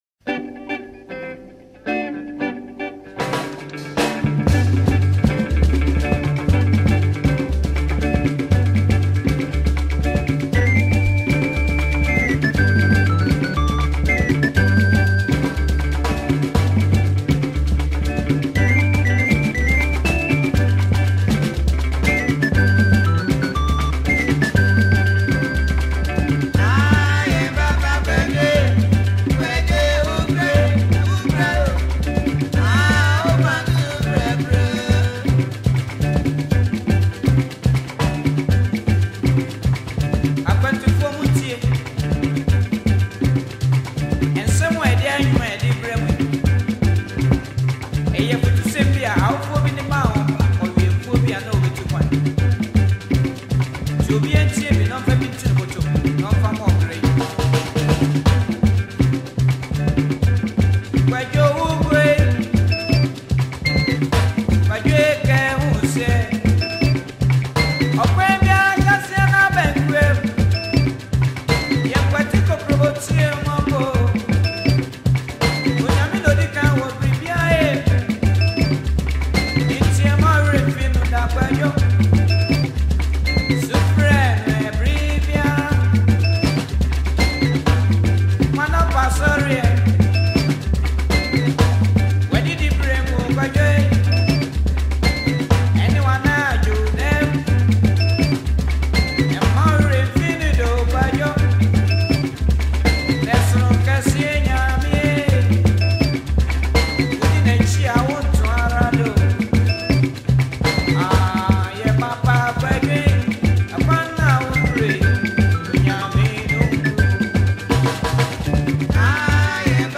Ghana Highlife song